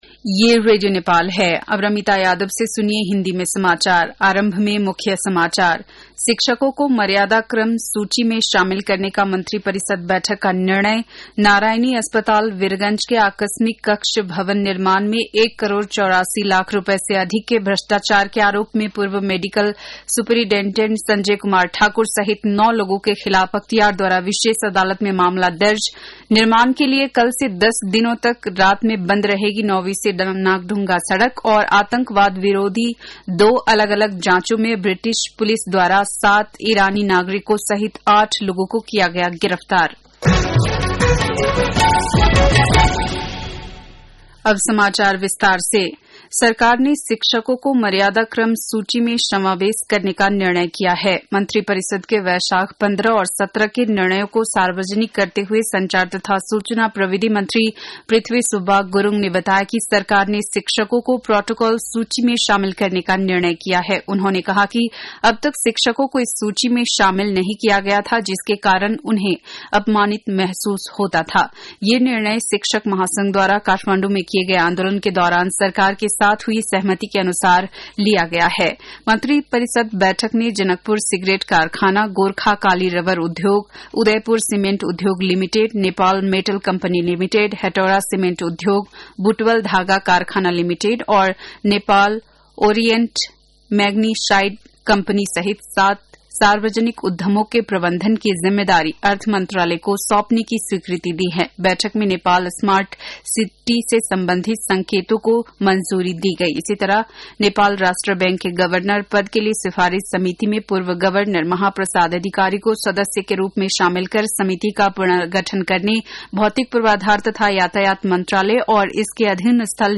बेलुकी १० बजेको हिन्दी समाचार : २१ वैशाख , २०८२
10-pm-hindi-news.mp3